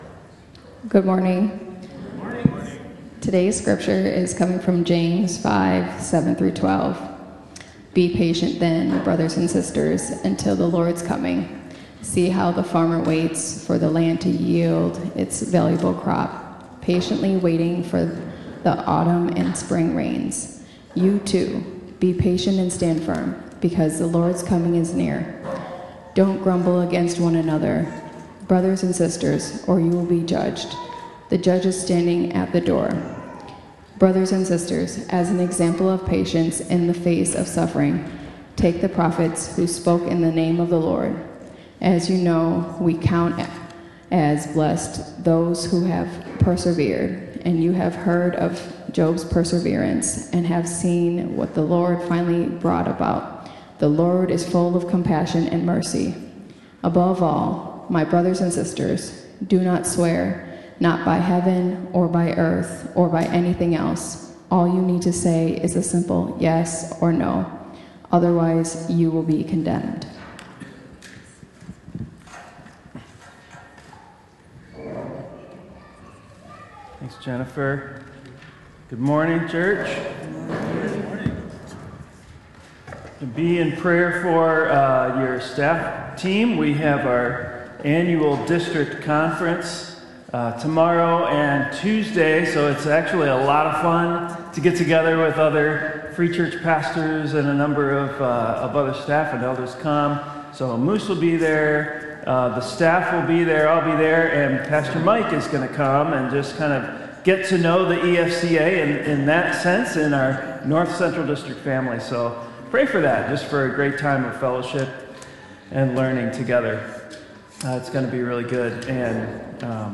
Sermon: James: How to Thrive in a Chaotic World | Antioch Community Church - Minneapolis
sermon-james-how-to-thrive-in-a-chaotic-world.m4a